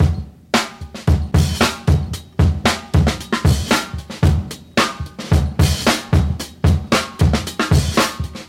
• 113 Bpm Drum Loop Sample E Key.wav
Free breakbeat sample - kick tuned to the E note.
113-bpm-drum-loop-sample-e-key-AOQ.wav